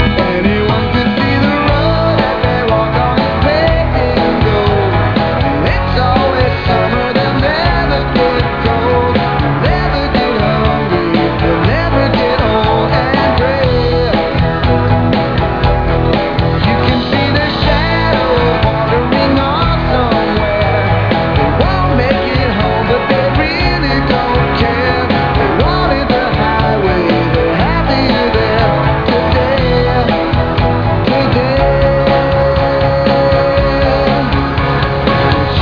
den rockar fett!